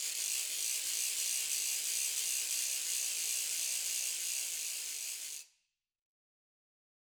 Ratchet1-Fast_v1_rr1_Sum.wav